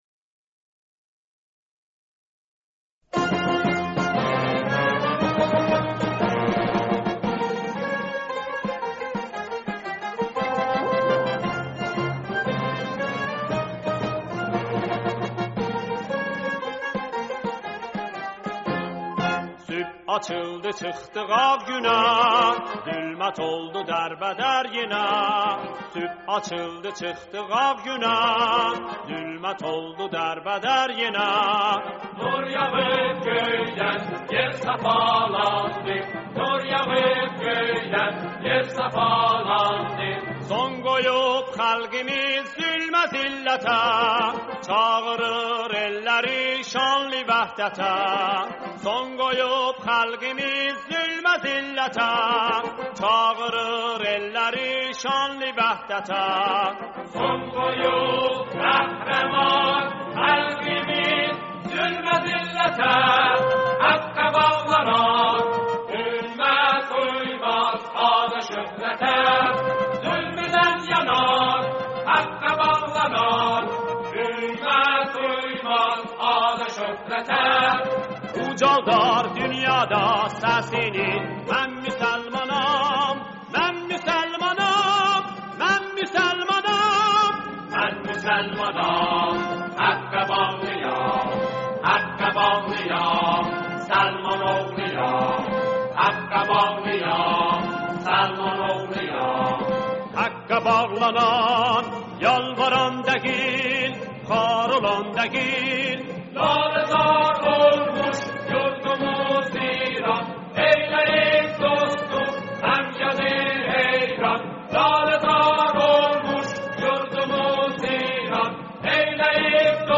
سرودی به گویش آذری
همراهی گروه كر